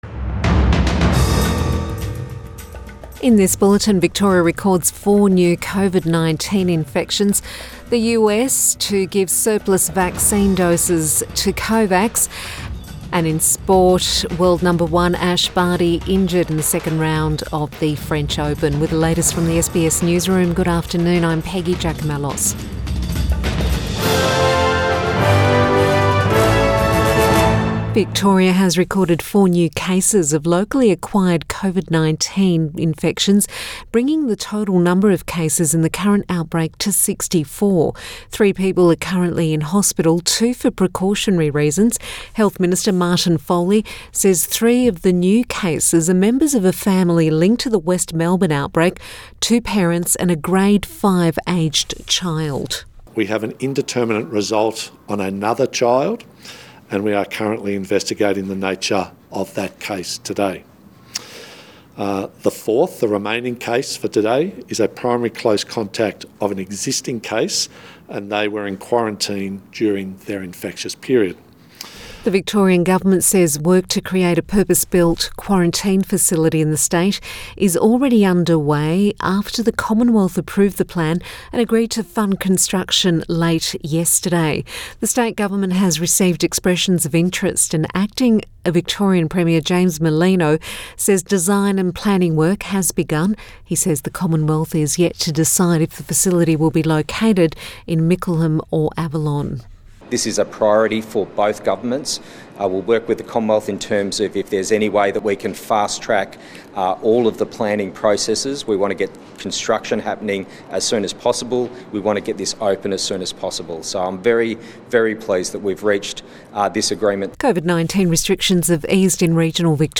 Midday bulletin 4 June 2021